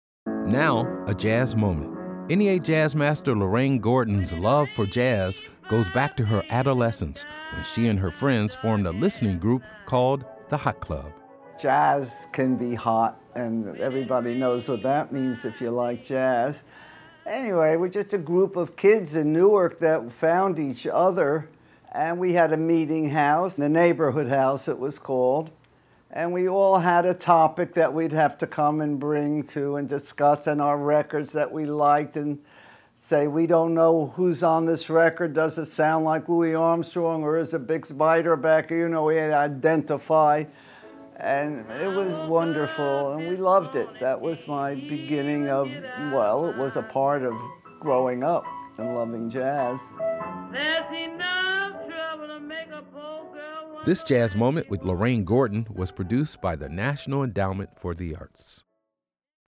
Excerpt of "Backwater Blues" written and performed by Bessie Smith with  James P. Johnson, from the album Essential Bessie Smith, used courtesy of Sony Music Entertainment and used by permission of the MPL Communications (BMI).